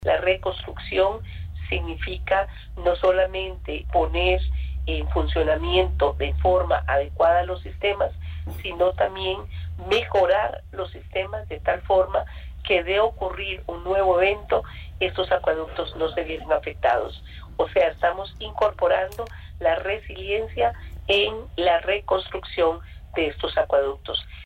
Astorga también comentó que la reconstrucción no estuvo solamente enfocada en volver a poner en funcionamiento los acueductos, sino que podrán soportar cualquier otro desastre natural.